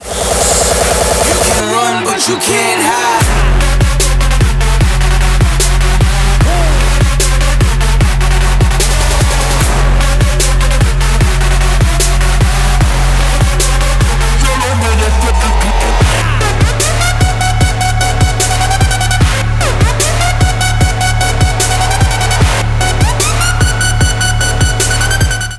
громкие
Electronic
Trap
качающие
Bass